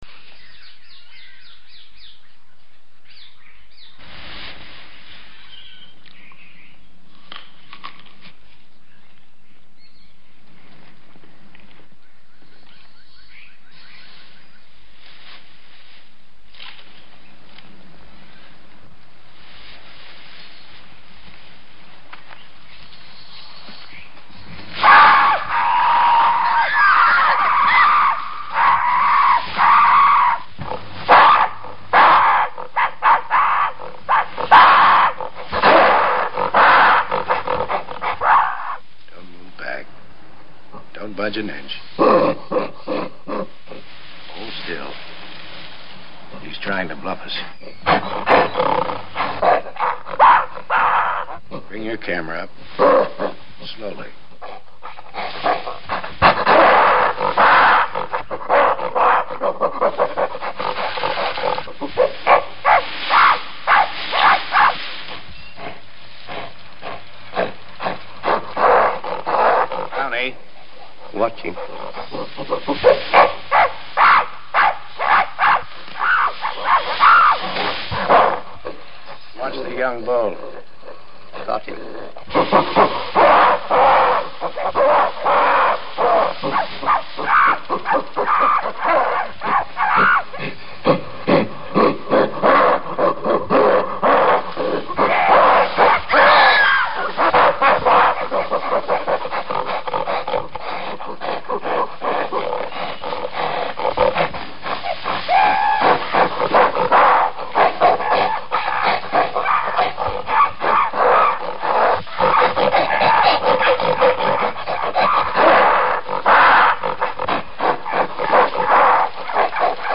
La bande son de 3mn relative à cette séquence est tout à fait remarquable. Il faut l’entendre de manière musicale. Les cris d’un bébé gorille ponctuent la fin de la séquence sonore. On reconnaîtra également les cris poussés par Grâce Kelly à la vue des gorilles menant une charge dissuasive. Et le « cinéma » s’entend lui-même à travers le bruit du moteur de la caméra en train de filmer.
Le choeur des gorilles :